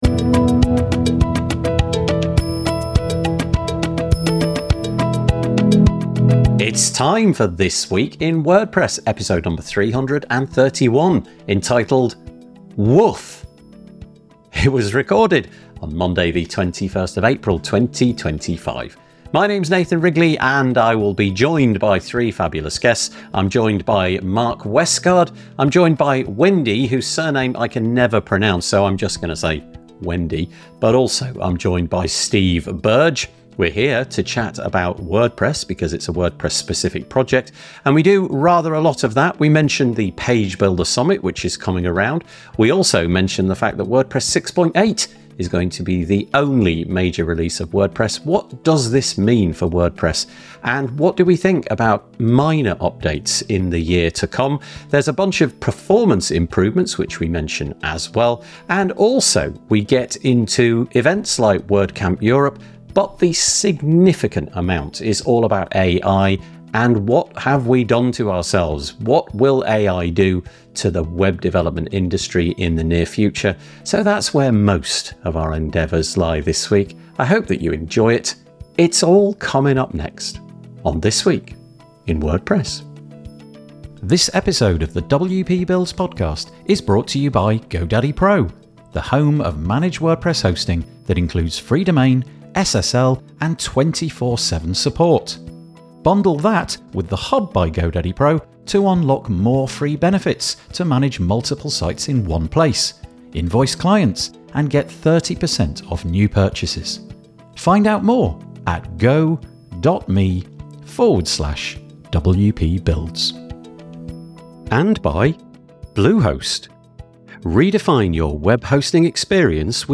We discuss the upcoming Page Builder Summit, the release of WordPress 6.8 (the only major update for 2025), and new features like speculative loading for faster page speed. We explore the environmental and practical impacts of prefetching, the shift to an annual WordPress release cadence, and concerns about AI’s growing role in web development. The panel also touches on community events like WordCamp Europe, the importance of human connection, and shares news about plugins, accessibility tools, and open-source site projects.